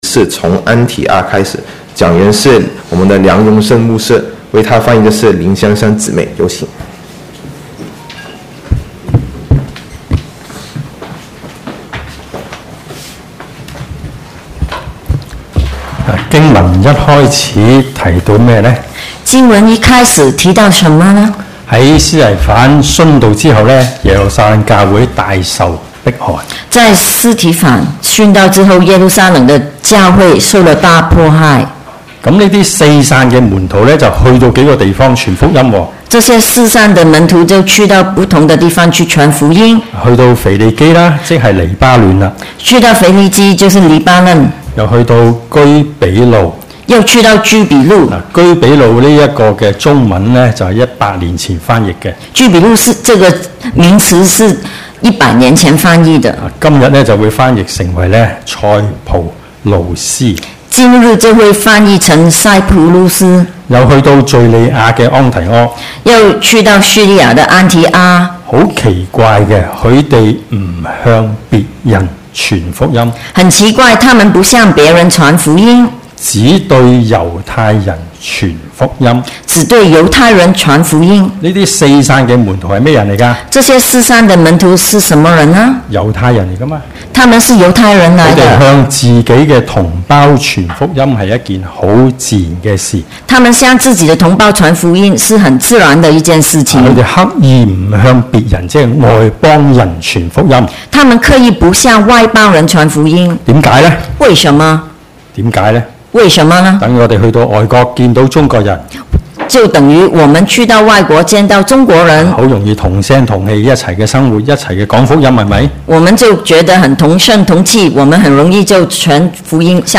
Posted in 主日崇拜